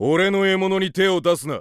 File:Falco voice sample SSBU JP.oga
Falco_voice_sample_SSBU_JP.oga.mp3